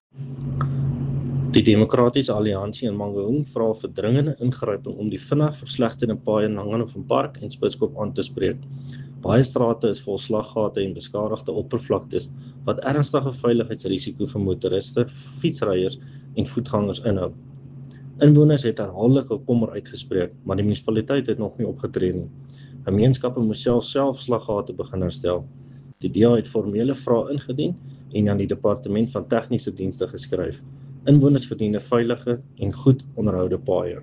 Afrikaans soundbites by Cllr Jan-Hendrik Cronje and